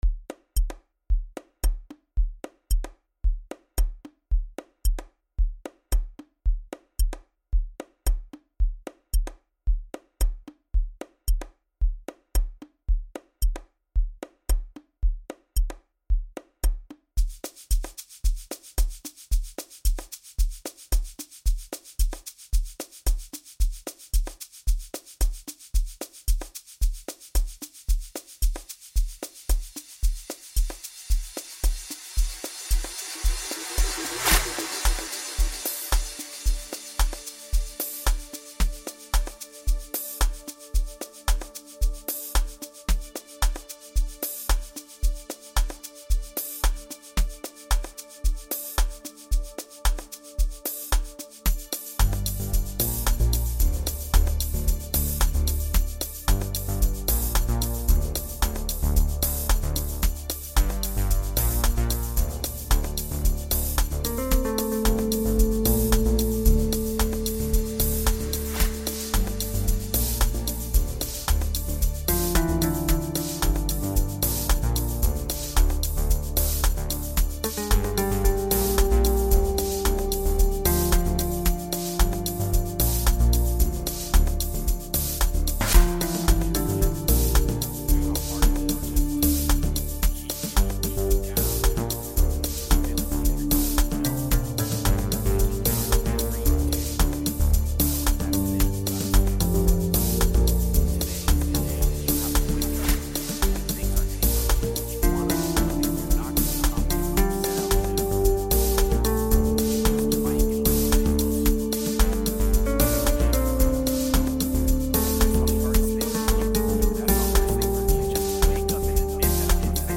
infusing piano elements with soulful vibes